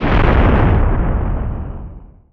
Explosion and footstep SFX
EXPLDsgn_Explosion Rumble Distorted_01.wav